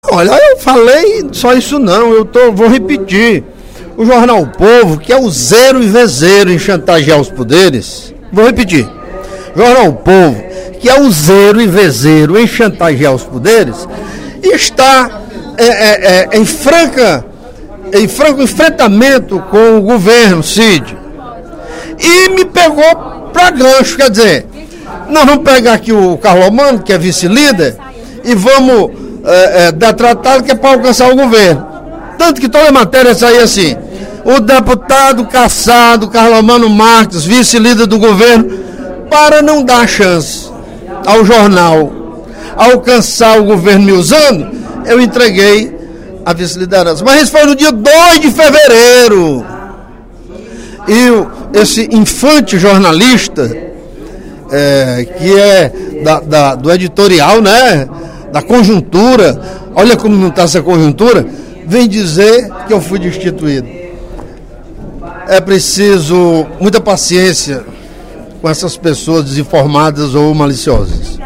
O deputado Carlomano Marques (PMDB) declarou, em pronunciamento durante o primeiro expediente da Assembleia Legislativa desta quinta-feira (06/06), que o objetivo do jornal O Povo, ao produzir denúncias contra ele, é, na realidade, alcançar o Governo do Estado.